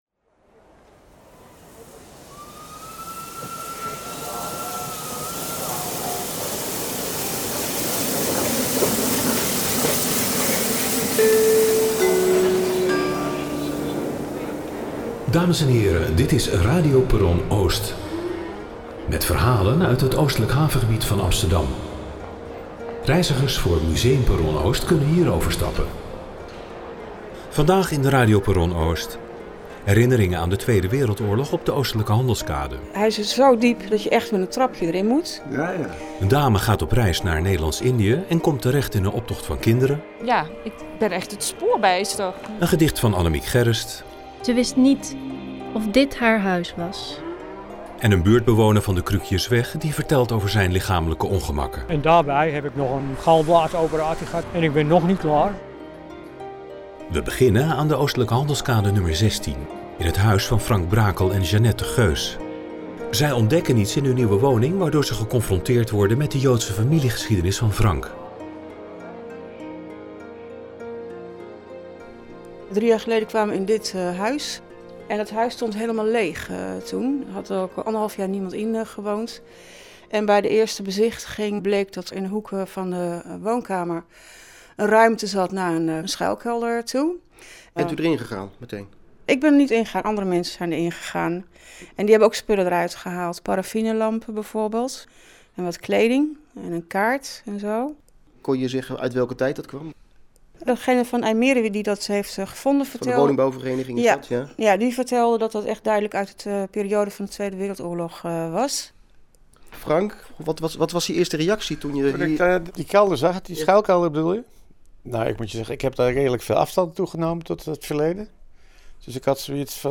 In deze derde radio uitzending van Perron Oost